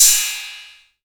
• Crash Cymbal Drum Sample D# Key 18.wav
Royality free crash cymbal drum sample tuned to the D# note.
crash-cymbal-drum-sample-d-sharp-key-18-It5.wav